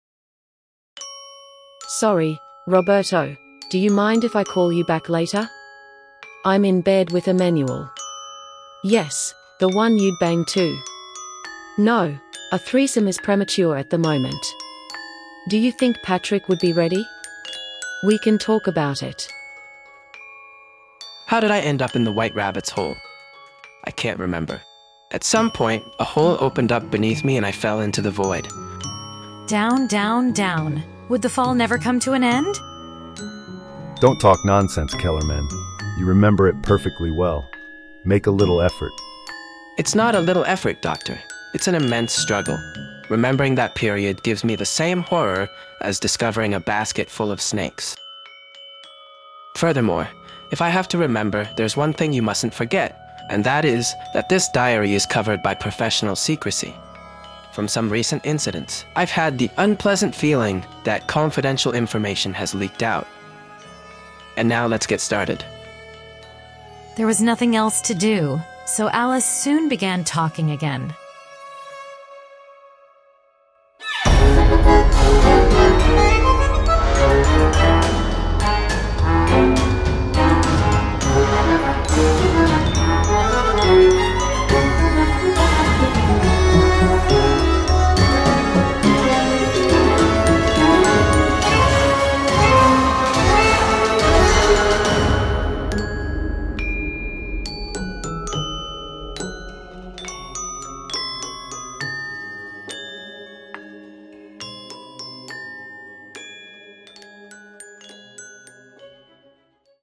All interpreters are AI.